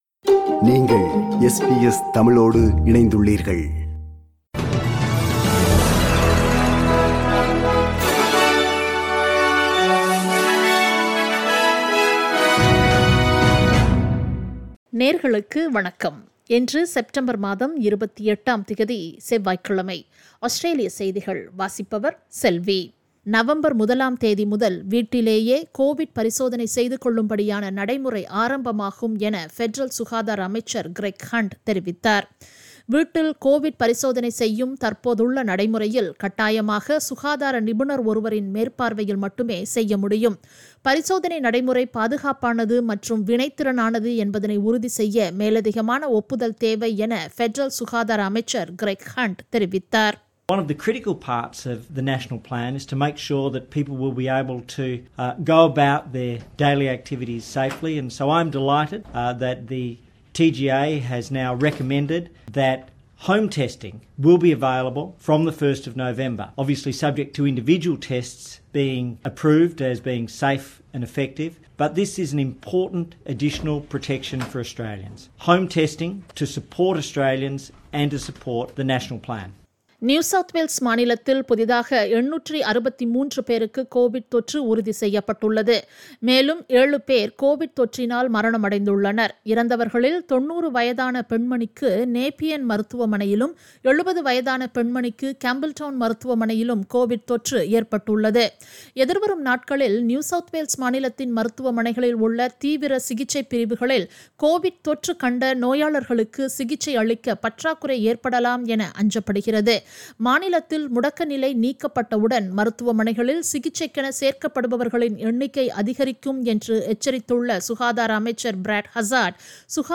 Australian news bulletin for Tuesday 28 September 2021.
australian_news_28_sep_-_tuesday.mp3